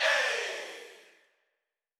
SouthSide Chant (22)(1).wav